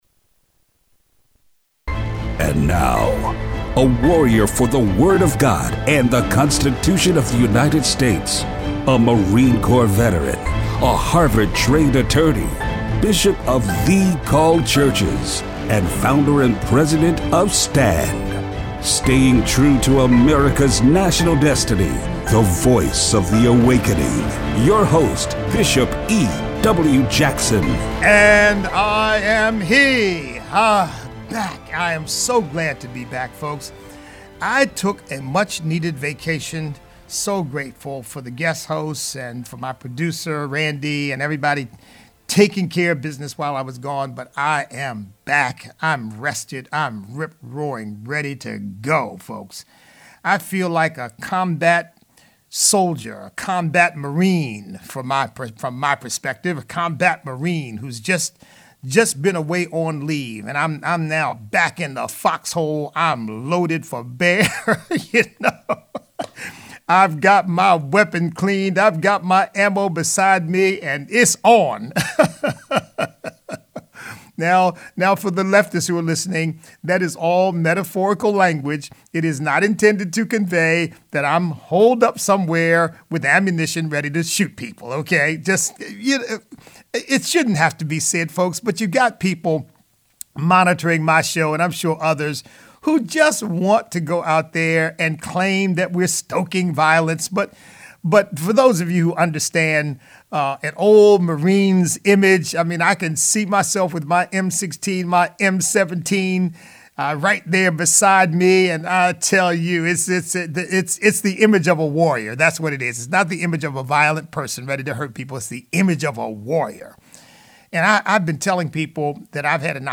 Our elections need to be cleaned up so that we do not have a repeat of the chaos of 2020. Impeachment trial and listener call-in.